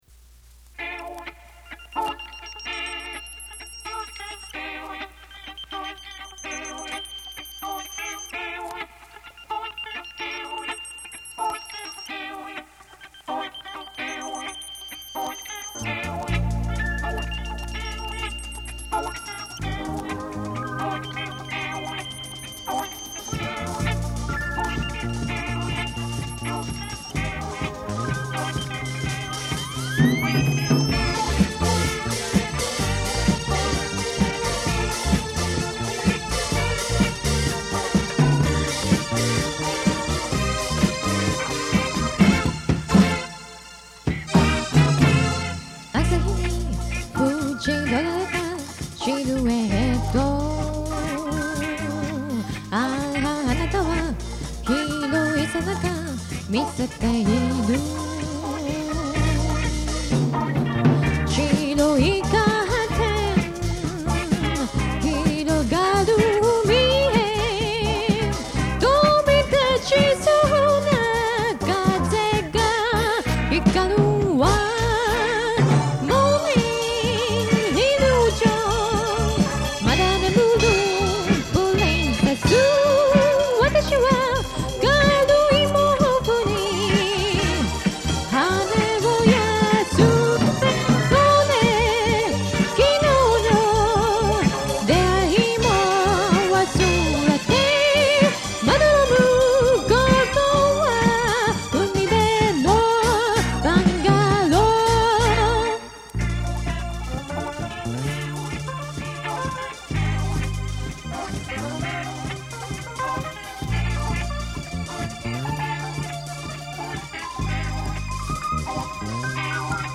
ここで紹介させていただきますのは，もう２０余年前，大学の頃，ひとり軽音楽部の部室で，多重録音で作ったオリジナル曲です。